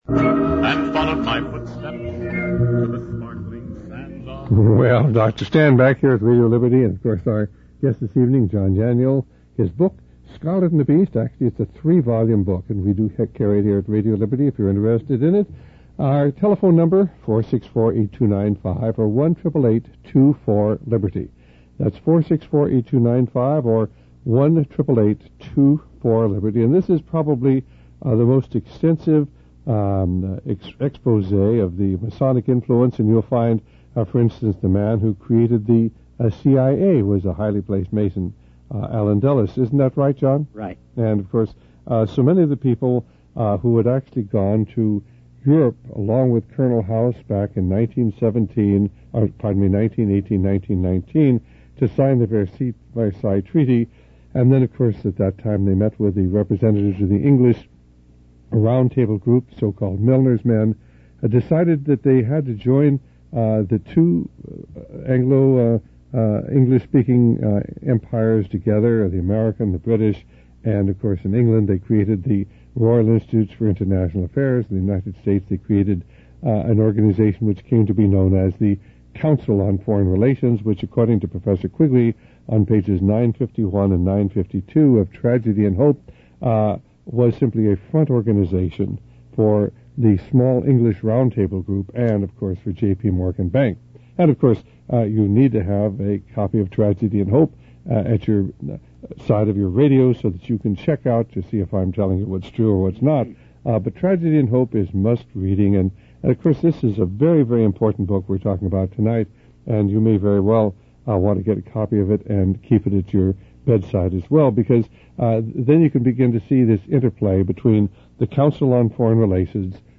In this sermon, the preacher discusses the negative impact of video games on the minds of young people. He argues that these games teach them how to kill easily and desensitize them to the value of human life.